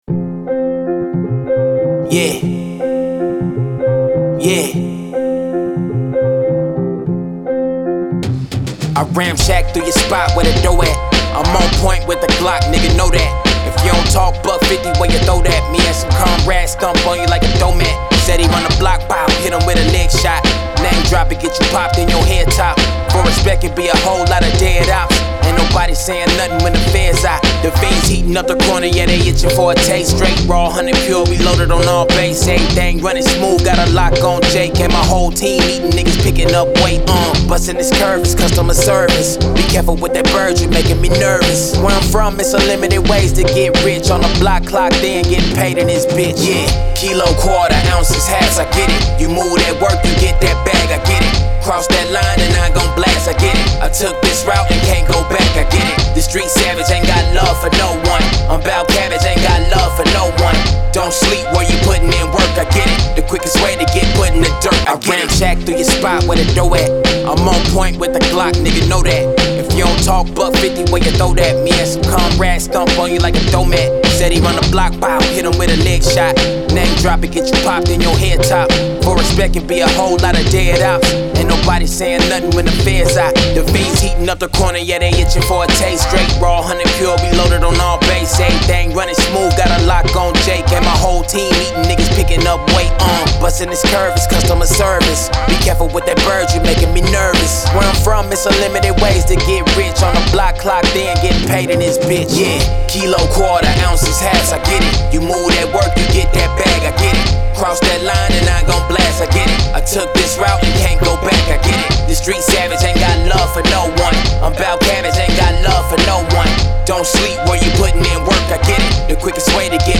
90s, Hip Hop
F major